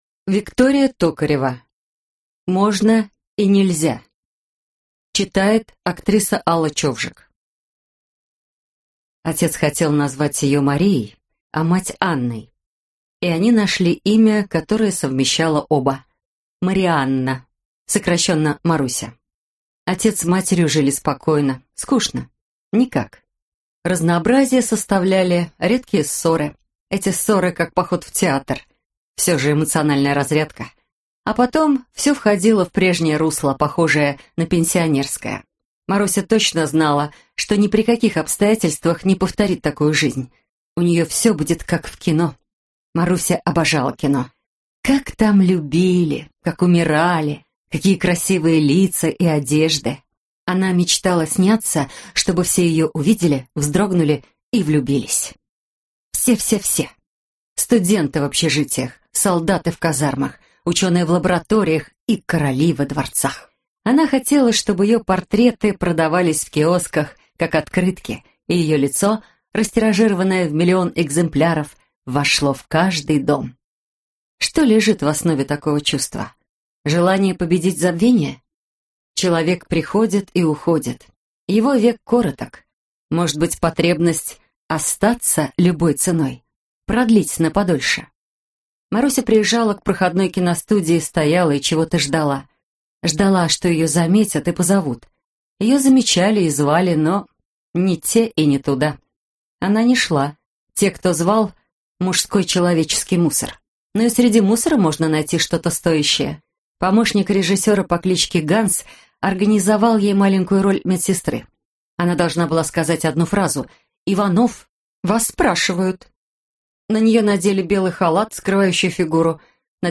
Аудиокнига Можно и нельзя (сборник) | Библиотека аудиокниг